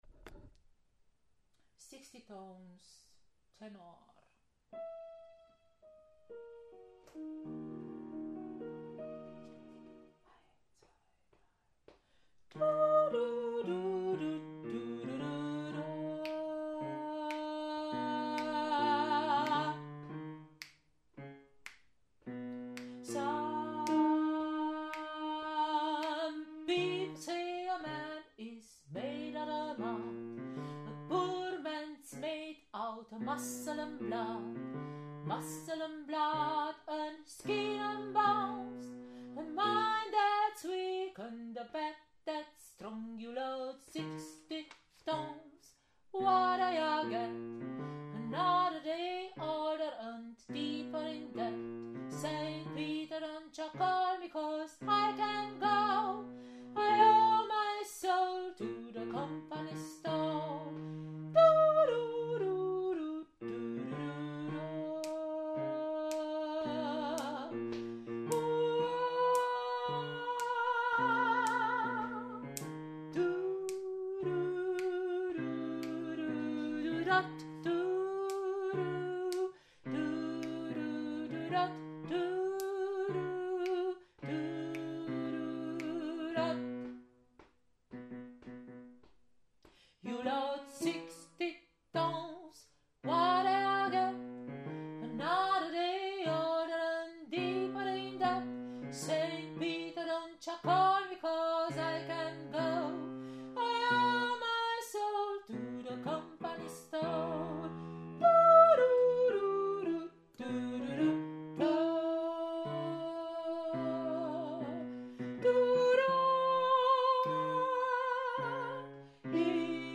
Von 19:15 bis 20:00 Uhr proben die NoNames, unser kleines, aber feines, Männerensemble, am gleichen Ort.
Sixteen-Tons-Tenor.mp3